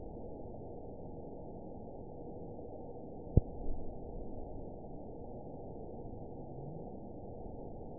event 920333 date 03/16/24 time 23:26:37 GMT (1 year, 3 months ago) score 9.40 location TSS-AB01 detected by nrw target species NRW annotations +NRW Spectrogram: Frequency (kHz) vs. Time (s) audio not available .wav